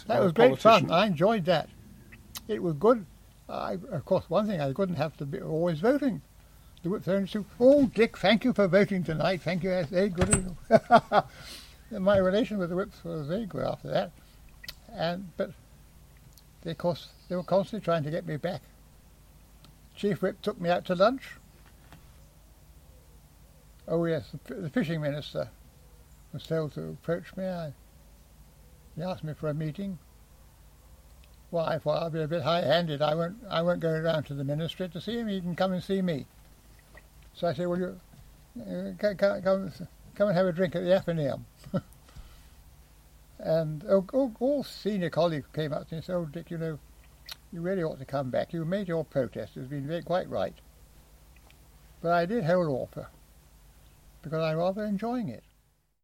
20th century history, Contemporary History, Diplomacy and International Relations, Factions, John Major, Modern, Oral history, Parties
For Sir Richard Body, MP for Holland and Boston, this was a period he enjoyed: